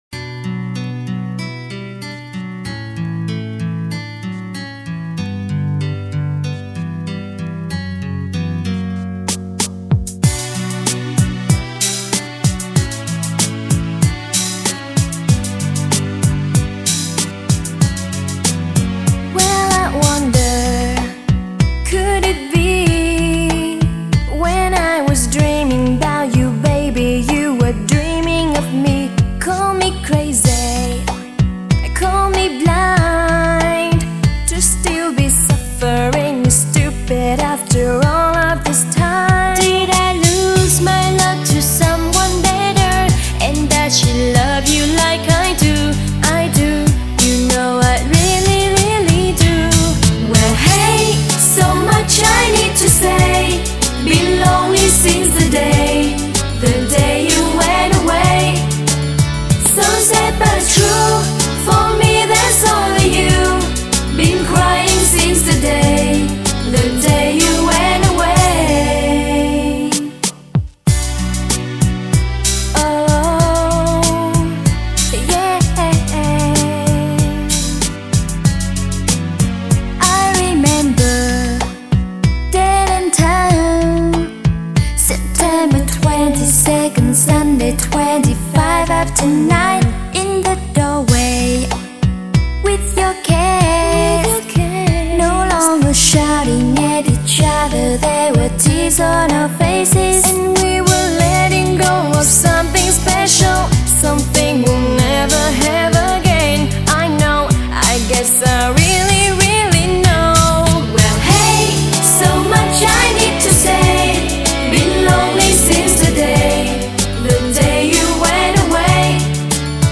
* Thể loại: Nhạc Ngoại Quốc